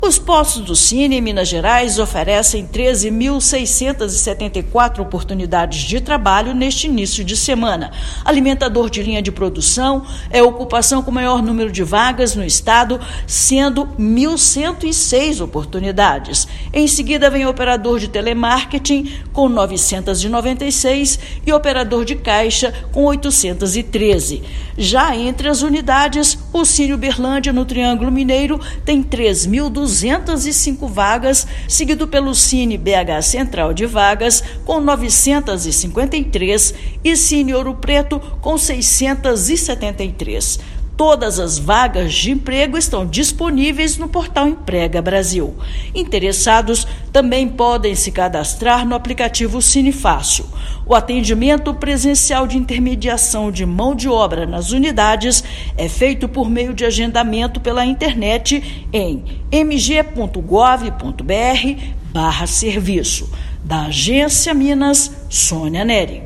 Alimentador de linha de produção é a ocupação com o maior número de vagas no estado. Ouça matéria de rádio.